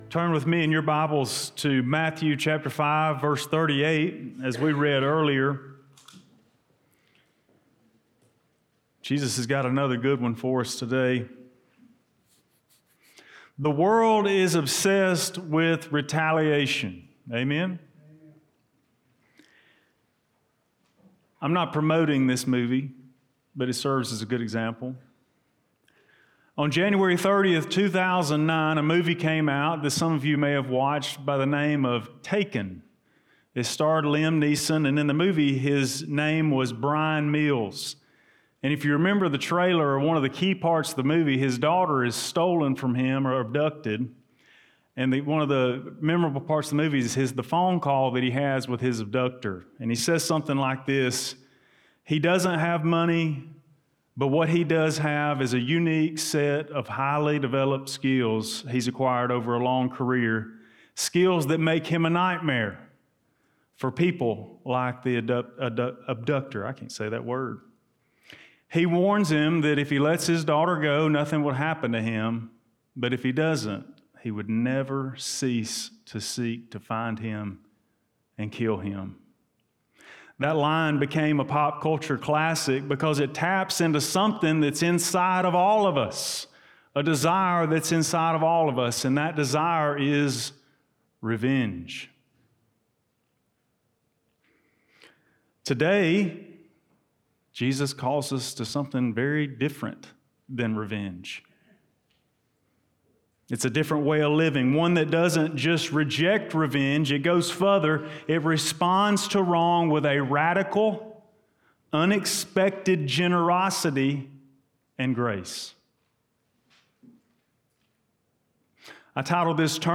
Blount Springs Baptist Church Sermons Turning the Other Cheek—What Did Jesus Really Mean?